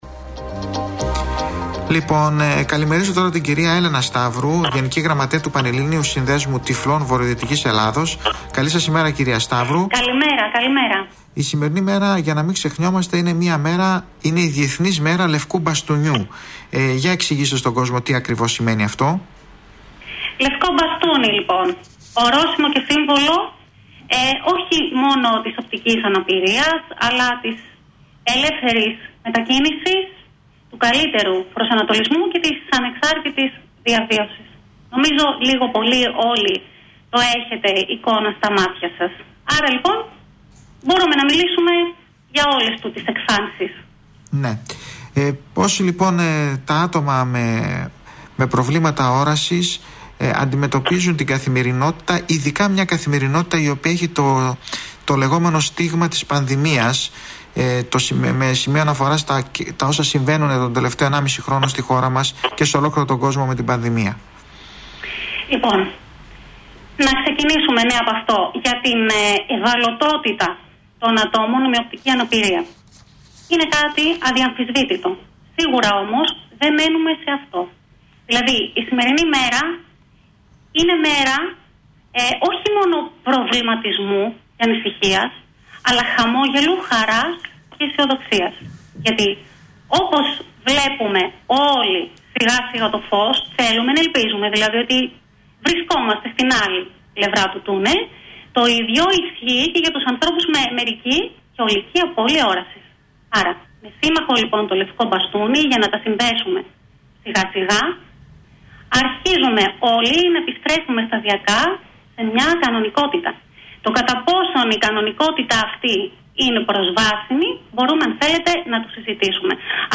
Την περασμένη Παρασκευή 15 του μήνα, με αφορμή τη διεθνή μέρα του λευκού μπαστουνιού, δεχτήκαμε με χαρά την πρόσκληση του Δημοτικού ραδιοφώνου Θεσσαλονίκης, για να μιλήσουμε ζωντανά για την τόσο σημαντική αυτή μέρα.
Ακολουθεί το ηχητικό απόσπασμα από τη συμμετοχή μας στην εκπομπή.